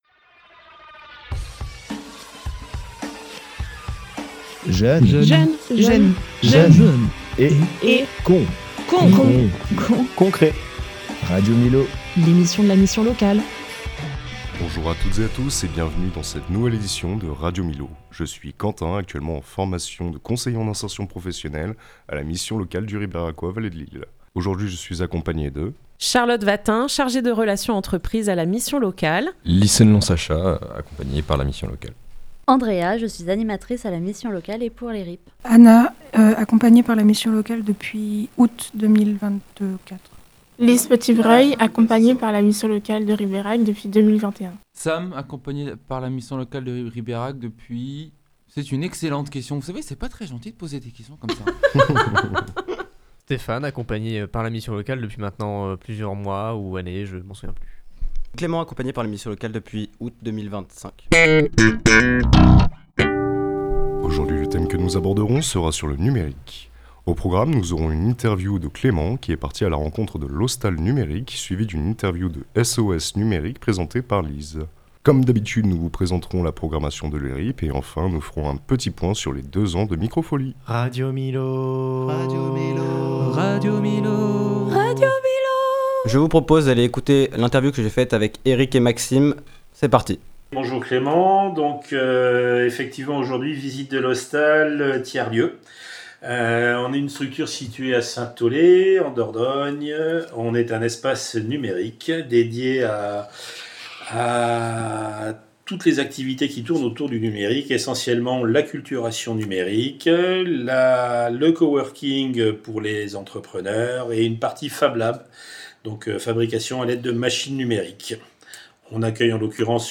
La mission locale et des jeunes prennent le micro.